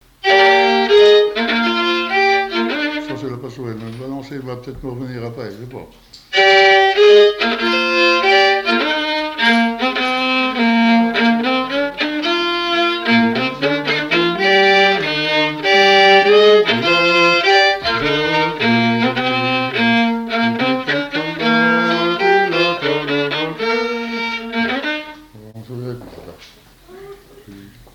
danse : quadrille : pastourelle
Le quadrille et danses de salons au violon
Pièce musicale inédite